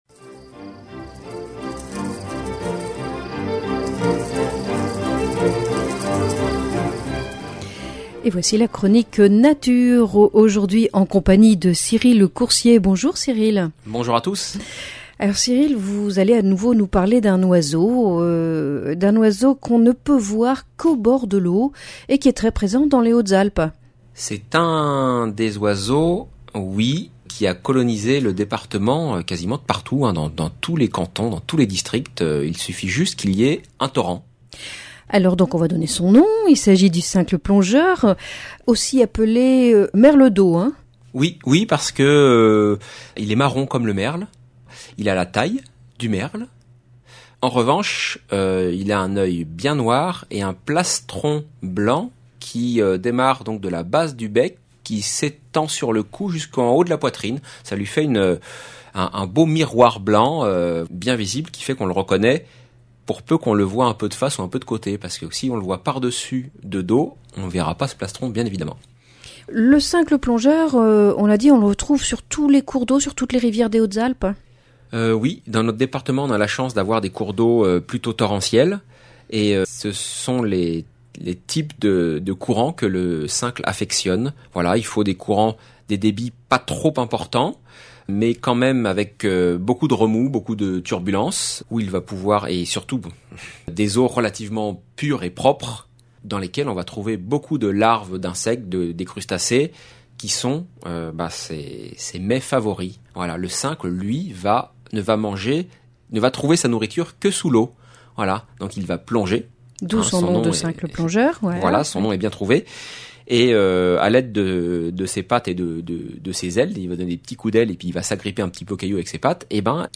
Chronique nature Pour découvrir cet oiseau bien présent dans les Écrins